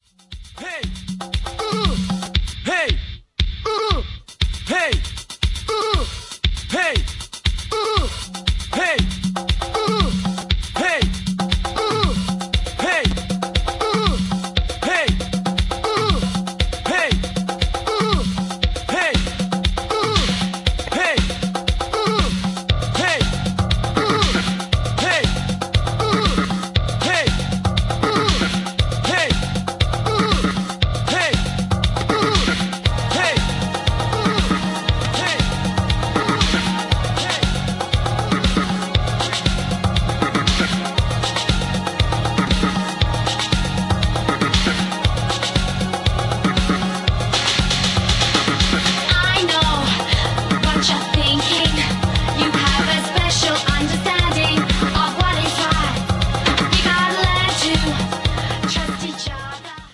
Industrial meets techno!